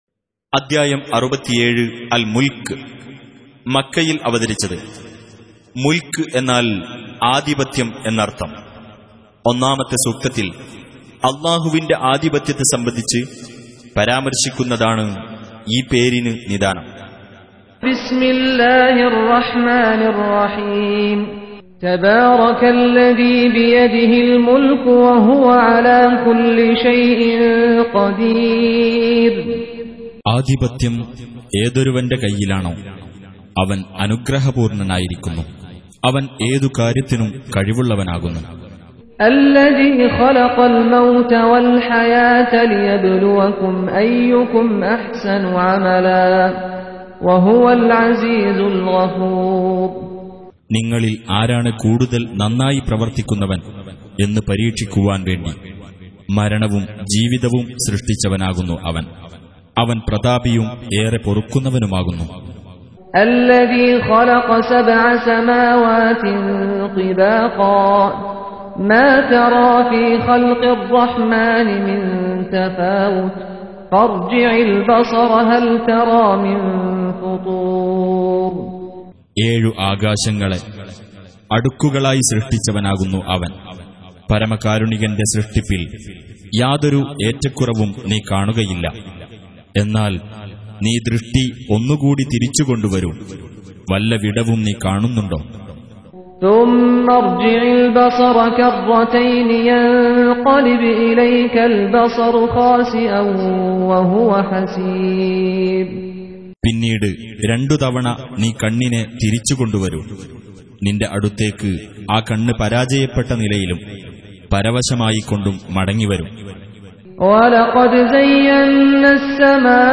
67. Surah Al-Mulk سورة الملك Audio Quran Tarjuman Translation Recitation